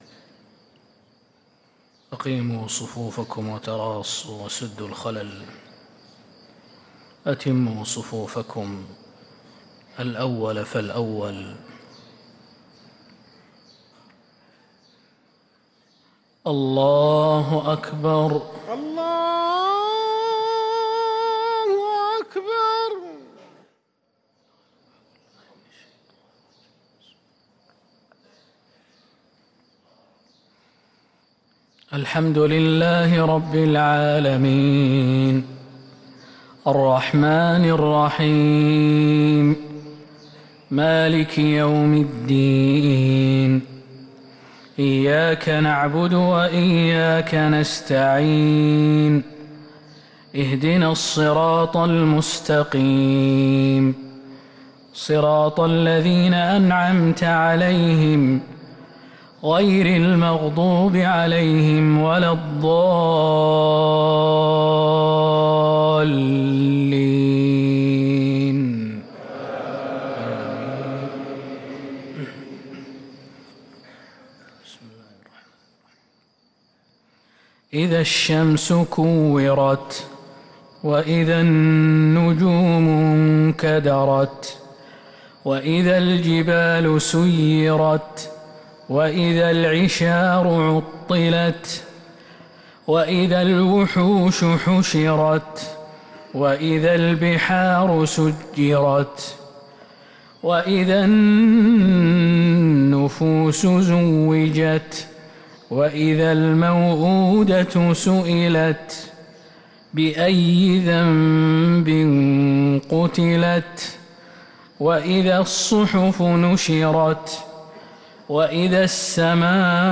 صلاة الفجر للقارئ خالد المهنا 12 شعبان 1444 هـ
تِلَاوَات الْحَرَمَيْن .